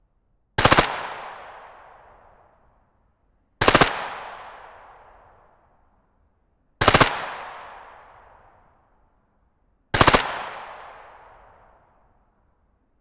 เสียงปืนเล็กยาว ตำรวจ
ริงโทนเสียงปืน เสียงปืนเล็กยาว
หมวดหมู่: เสียงต่อสู้อาวุธ
คำอธิบาย: เสียงเสียงปืนเล็กยาว ตำรวจเป็นเสียงที่คุ้นเคยในเกมและชีวิตประจำวัน มันเป็นเสียงของปืนที่ถูกยิงเพื่อคุ้กคามอาชญากร เป็นเสียงที่เราคุ้นเคยในสนามรบ และเป็นเสียงของปืนที่ถูกยิงในสงคราม